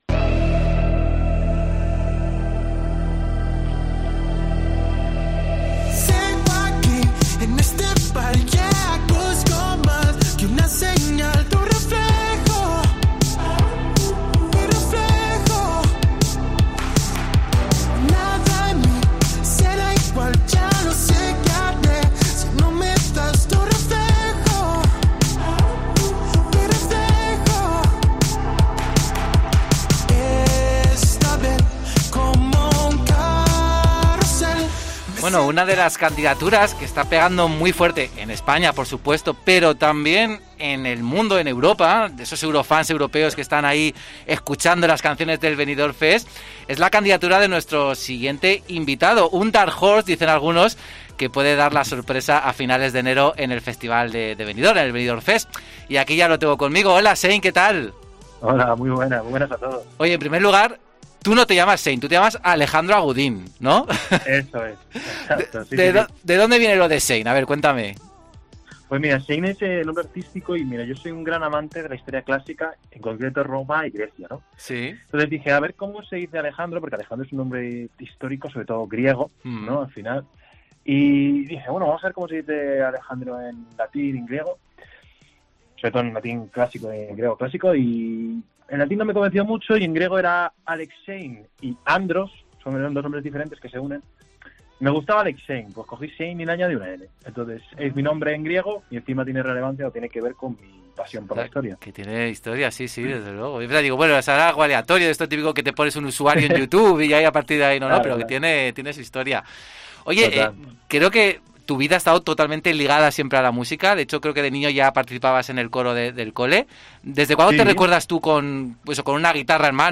Y nada más recibir la noticia, fui a por ello", ha dicho durante una entrevista en el programa "Pasaporte a Eurovisión" de COPE.